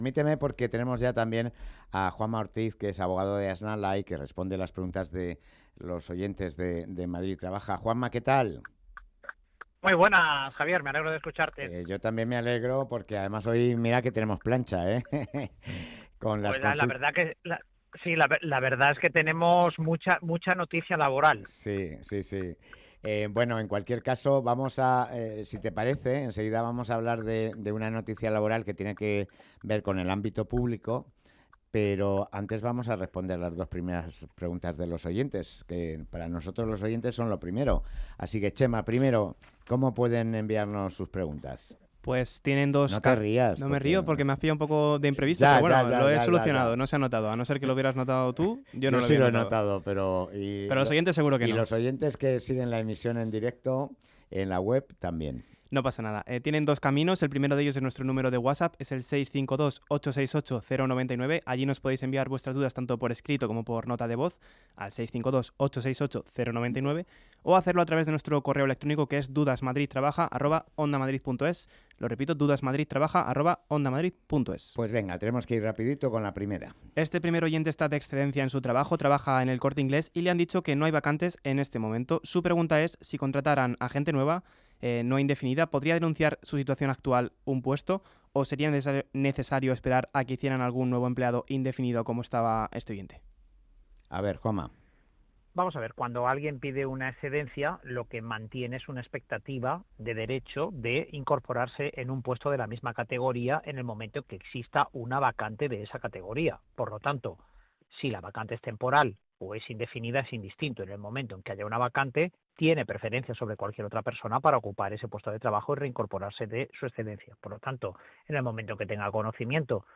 Entrevista
Hemos recibido en nuestro estudio de Pozuelo de Alarcón